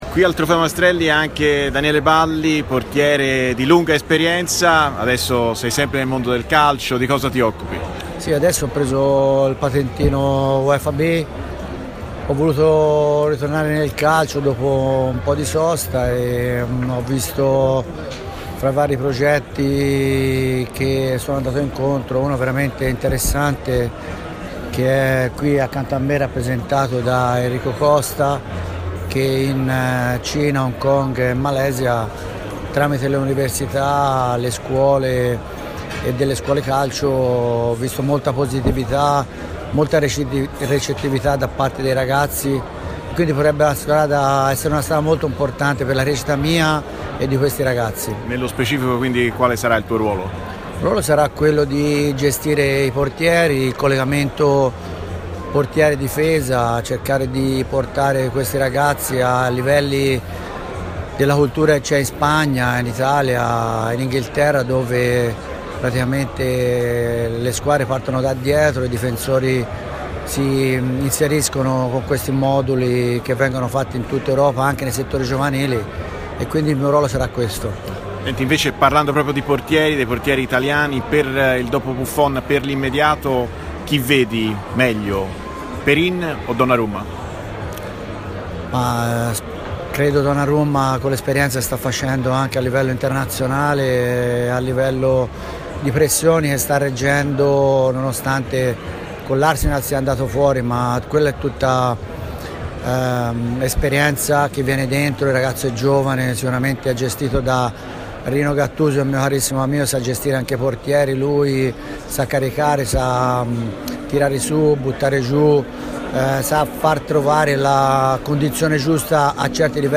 ex portiere, intervistato
al Premio Maestrelli